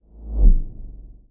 whoosh_left.mp3